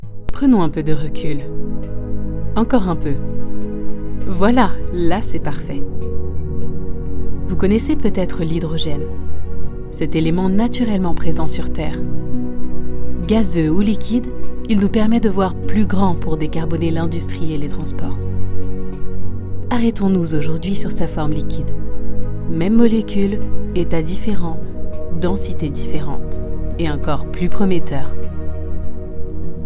Bande démo
Une voix qui ne triche pas, et une présence simple, chaleureuse et pleine de vie.
13 - 45 ans - Mezzo-soprano